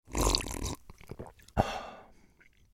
دانلود صدای آب 34 از ساعد نیوز با لینک مستقیم و کیفیت بالا
جلوه های صوتی
برچسب: دانلود آهنگ های افکت صوتی طبیعت و محیط دانلود آلبوم صدای آب از افکت صوتی طبیعت و محیط